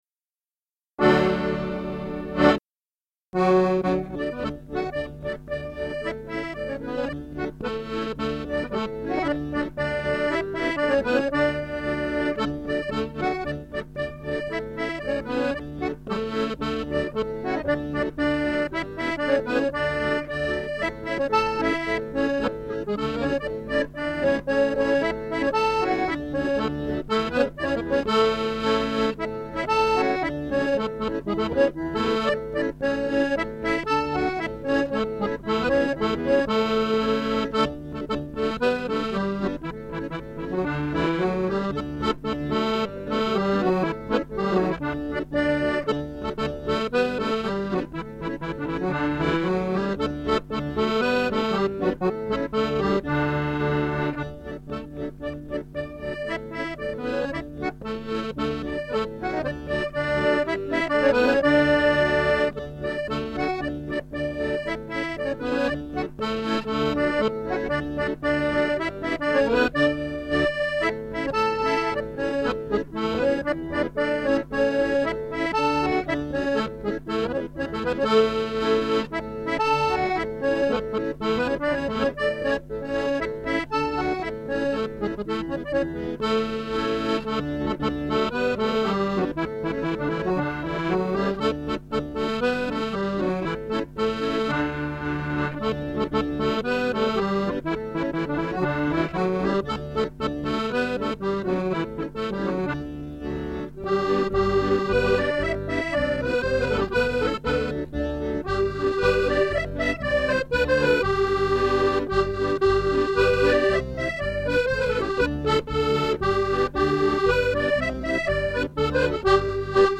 Music - 48 bar reels or jigs